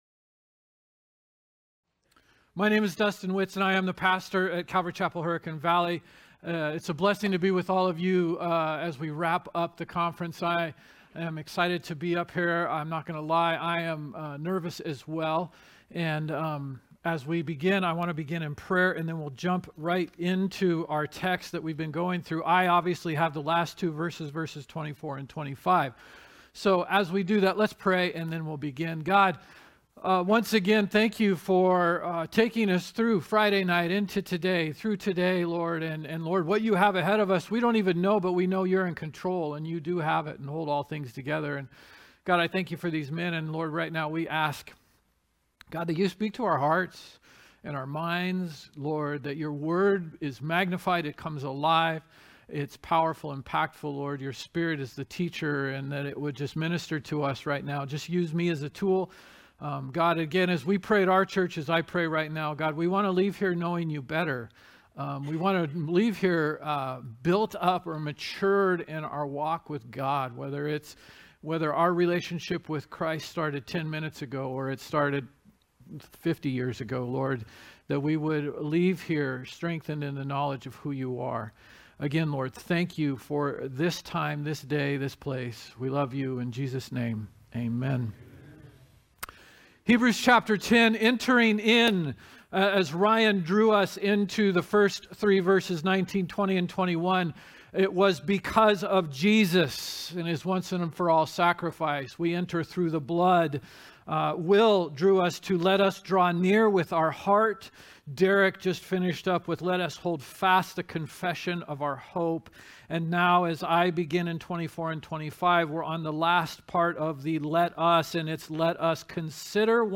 Calvary Chapel Saint George - Sermon Archive
From Series: "Men's Conference 2023"